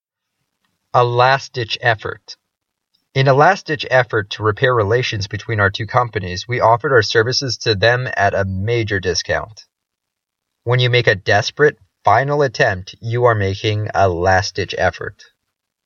英語ネイティブによる発音は下記のリンクをクリックしてください 。